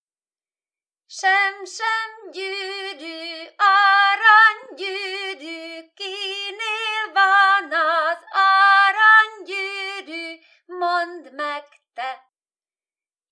Típus I. Népi játékok / 11.
5-6 év Évszak Tél Település Barslédec, Lédec [Ladice]